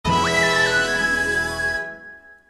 Disney Sms Sound Effect Free Download